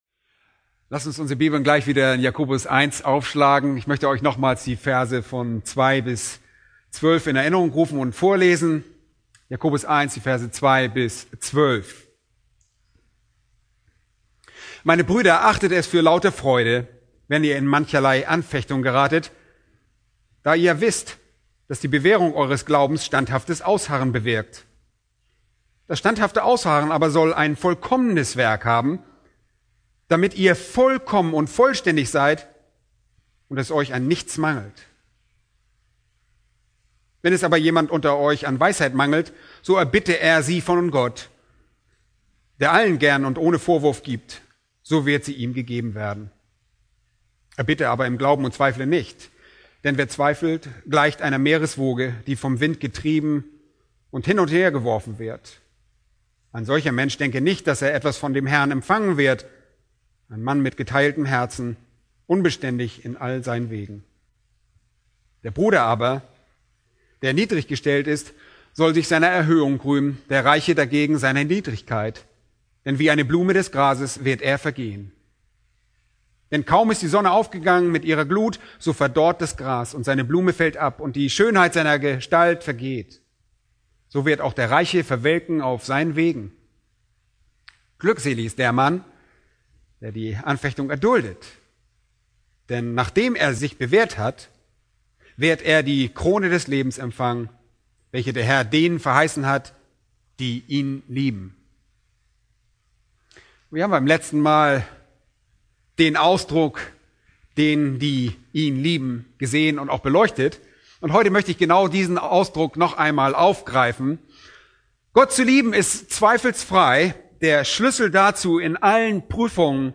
Predigt: "1.